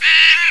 crow_ko.wav